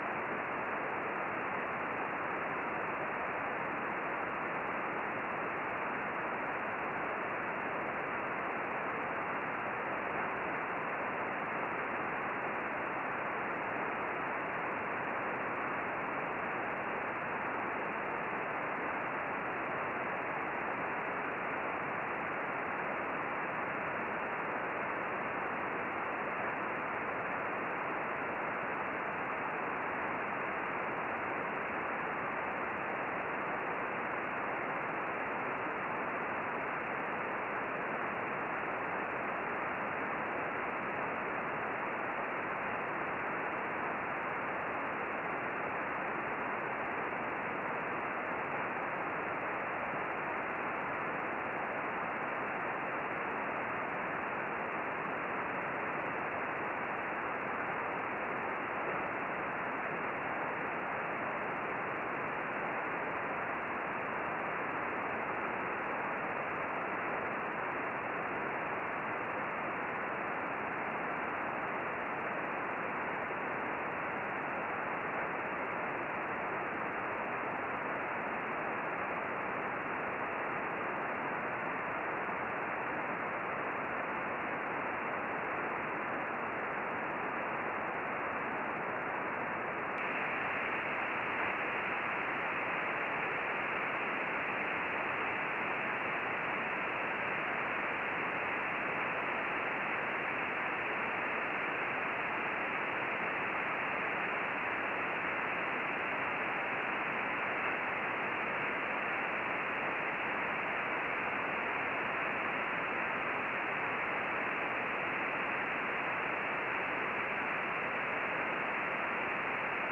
The noise floor barely moves when the preamp is switched in, while the desired beacon signal increases significantly.
I can reliably copy the RS-44 beacon about 8 degrees above the horizon, and SSB is quite readable at about 20 degrees.
I chase the beacon at the end in CW mode, and it disappears at 5 degrees elevation.) A FT-847 was used for reception, but a cheap SDR dongle would probably work just as well for a lost-cost entry into 70cm downlink capability (as required for the GOLF satellites).